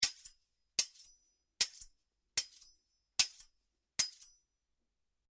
Afilando un cuchillo
Cocina - Zona de preelaboración
Sonidos: Acciones humanas